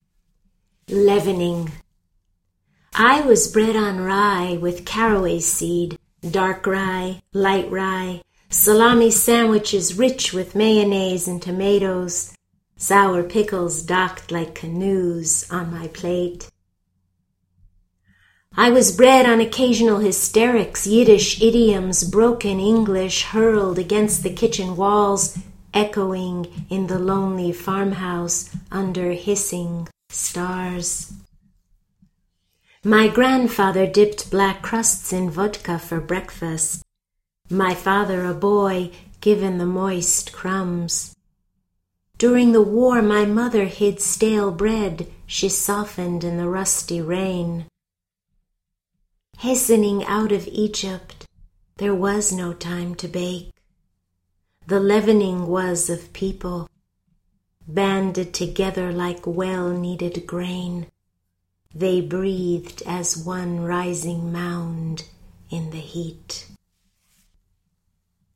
Your strong, clear, musical reading voice enhances your written words.